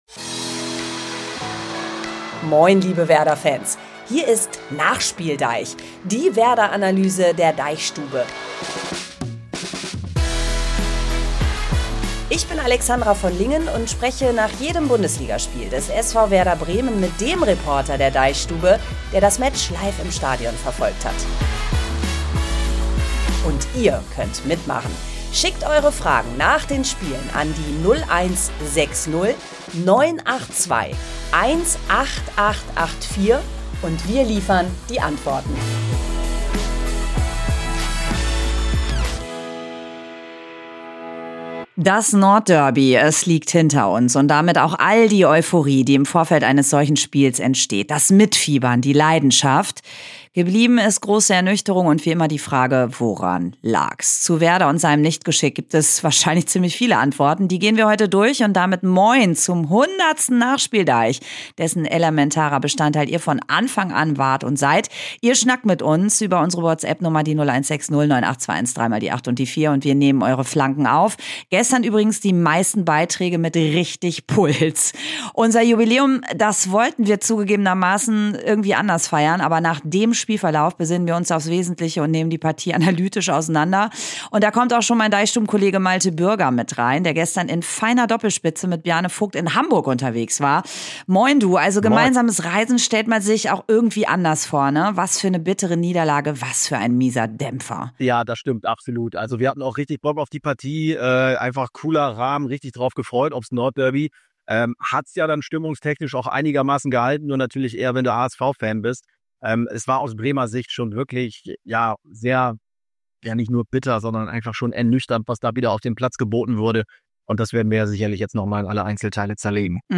NachspielDEICH ist ein Fußball-Podcast der DeichStube.